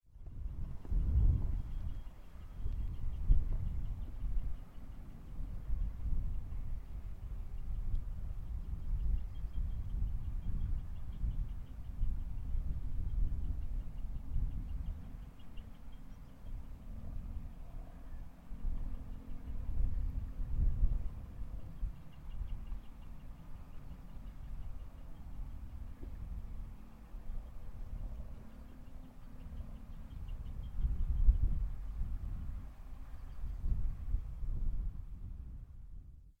a boat going down the thames